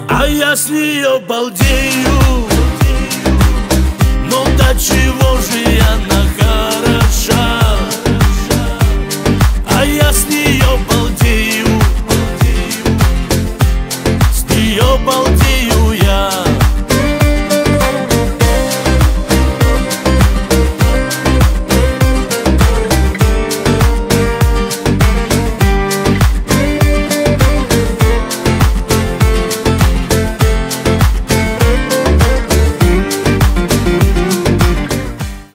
• Качество: 320, Stereo
гитара
мужской голос
спокойные